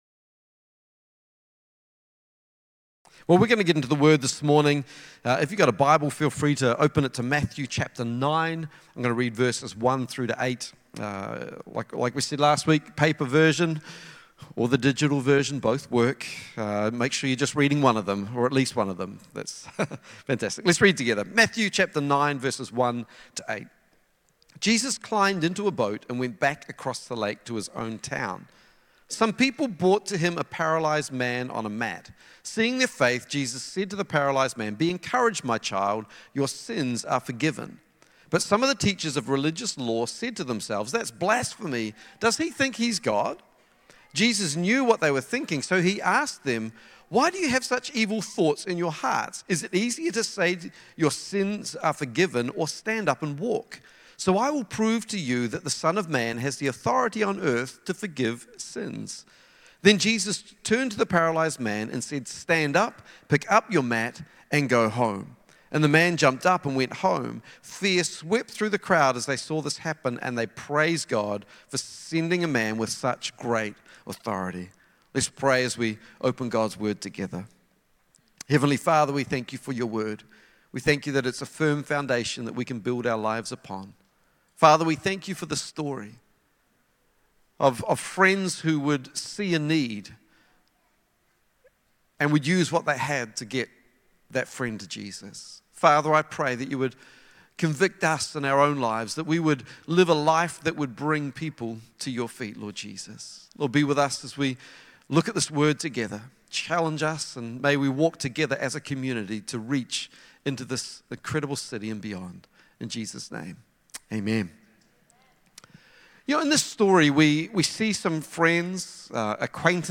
Sunday Messages Mat Carriers